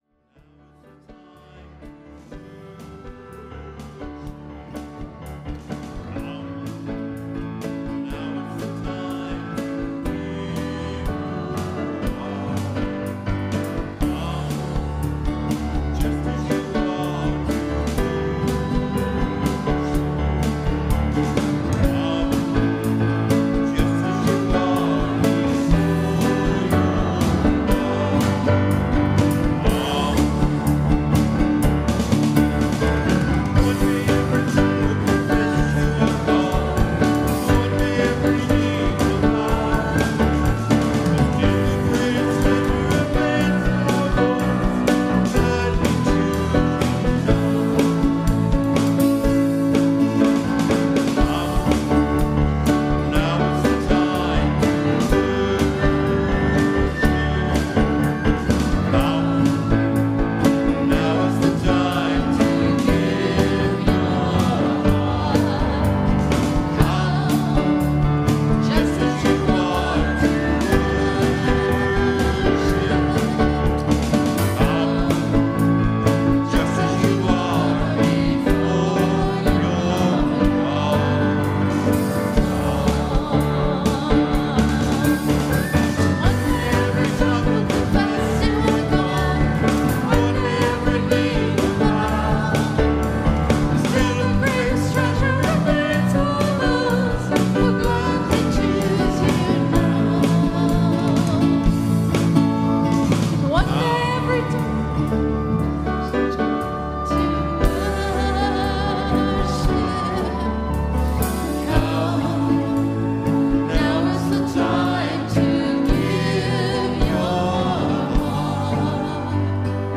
Worship and Sermon audio podcasts
WORSHIP - 10:30 a.m. Sixth of Easter